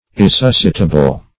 Search Result for " irresuscitable" : The Collaborative International Dictionary of English v.0.48: Irresuscitable \Ir`re*sus"ci*ta*ble\, a. Incapable of being resuscitated or revived.